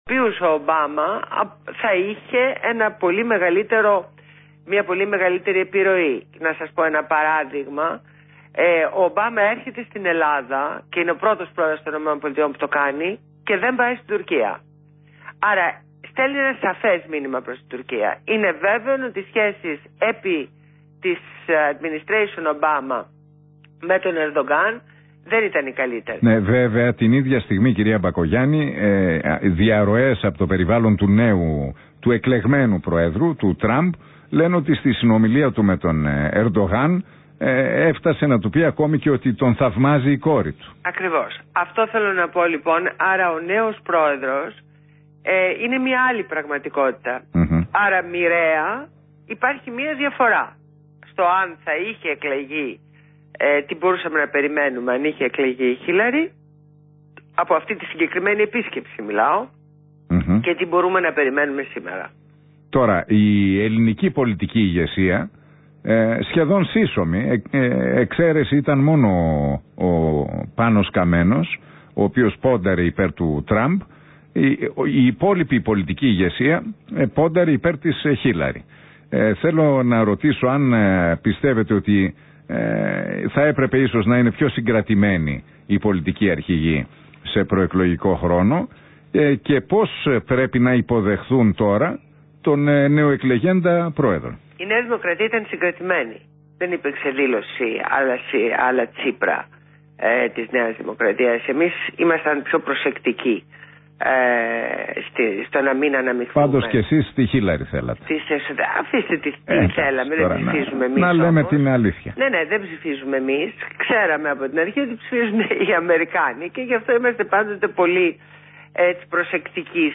Συνέντευξη στο ραδιόφωνο REALfm στο Ν. Χατζηνικολάου.